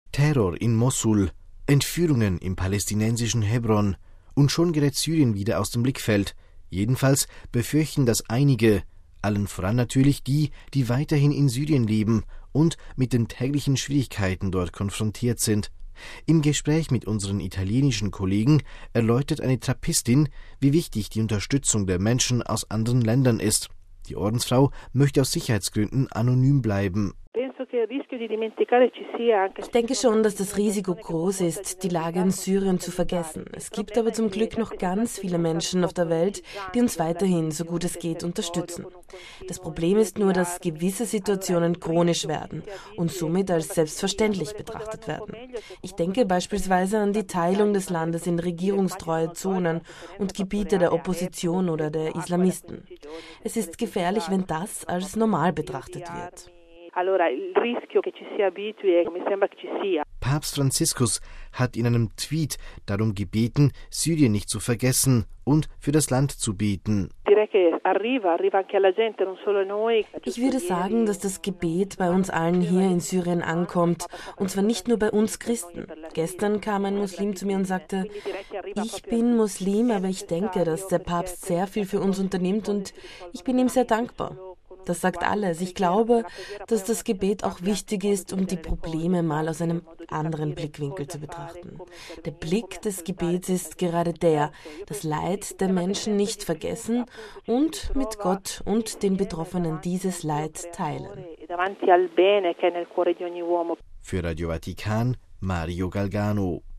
Jedenfalls befürchten das einige - allen voran natürlich die, die weiterhin in Syrien leben und mit den täglichen Schwierigkeiten dort konfrontiert sind. Im Gespräch mit unseren italienischen Kollegen erläutert eine Trappistin, wie wichtig die Unterstützung der Menschen aus anderen Ländern ist.